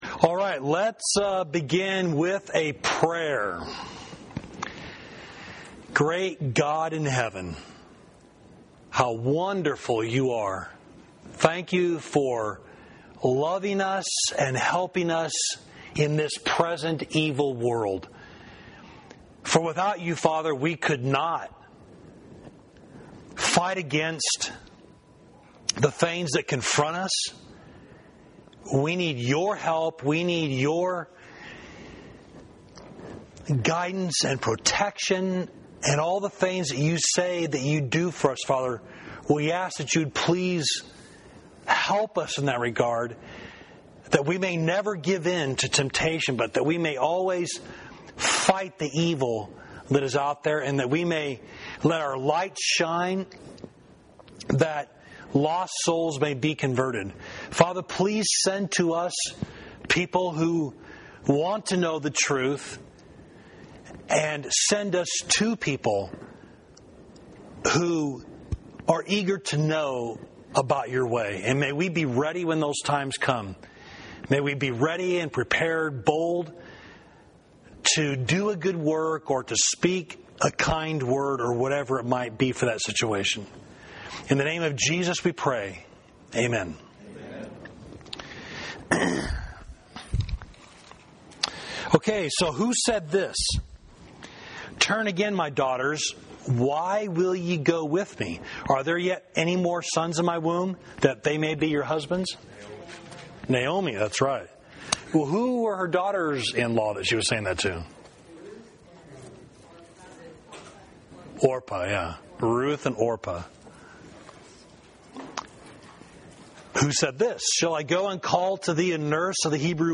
Class: Upon What “Rock” Did Jesus Build His Church? Did Peter lead heaven, or did heaven lead Peter?